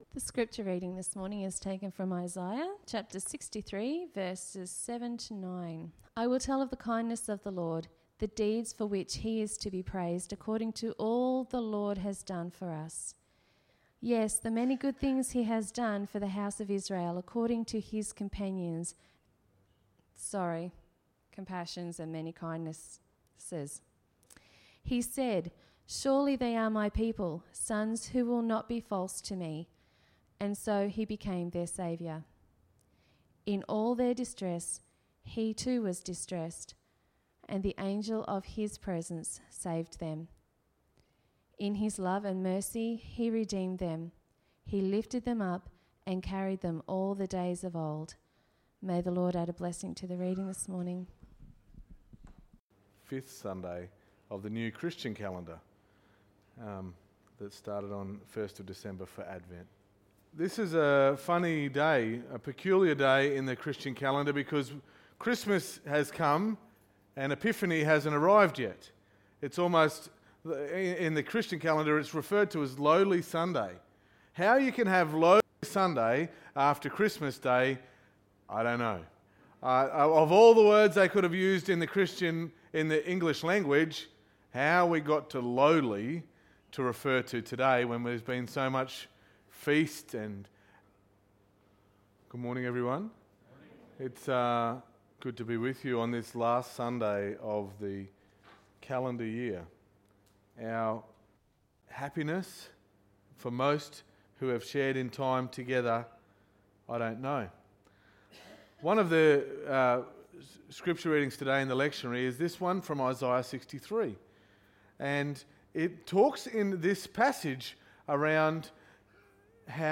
Sermon 29.12.2019